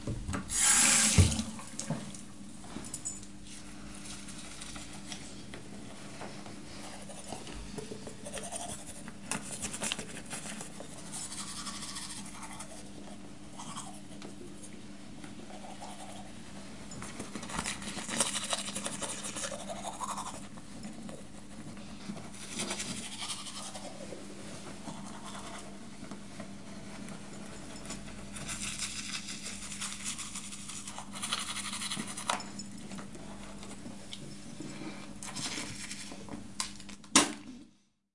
浴室 " 刷牙
描述：这里我录下了自己刷牙的过程。
Tag: 牙齿 浴室 刷涂